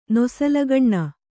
♪ nosalagaṇṇa